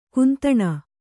♪ kuntaṇa